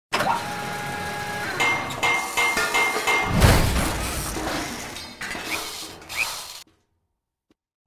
repair7.ogg